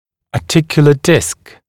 [ɑː’tɪkjulə dɪsk][а:’тикйулэ диск]суставный диск